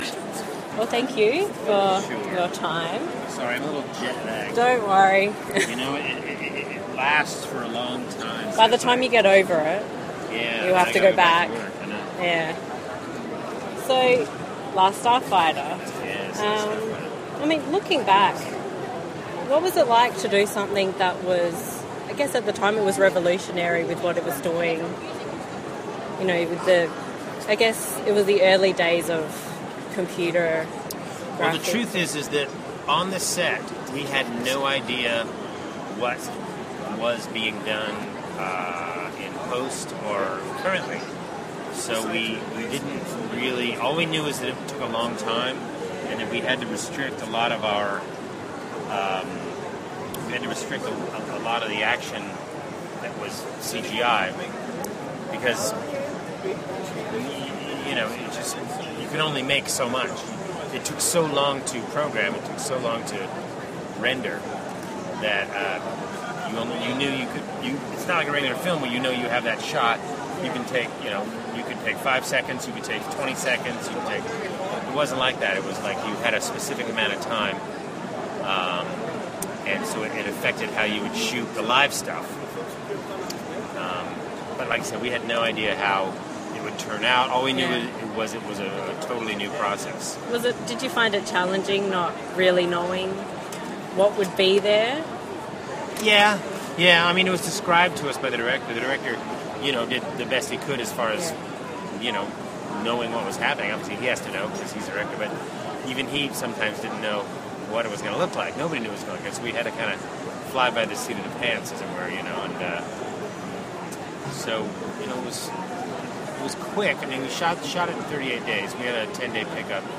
Armageddon Expo ’11 – Interview with Lance Guest
armageddoninterviewwithlanceguest.mp3